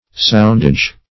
Soundage \Sound"age\ (?; 48), n. Dues for soundings.
soundage.mp3